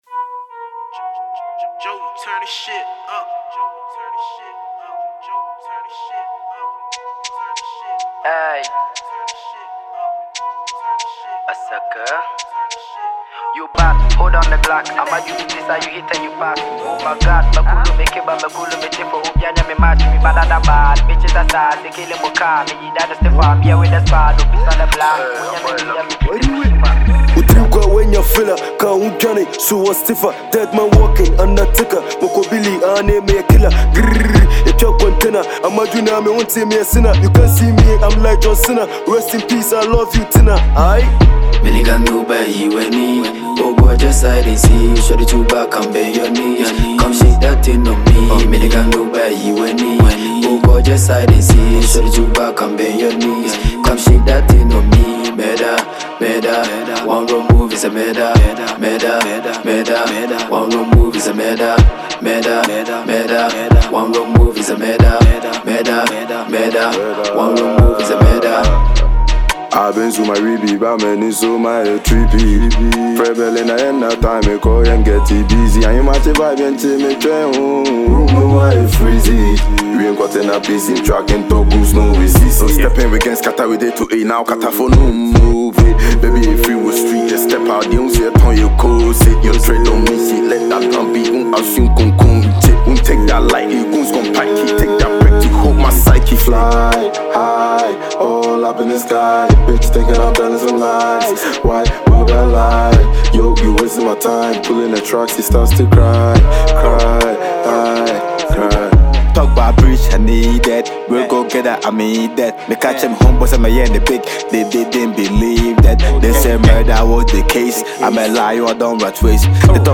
trap tune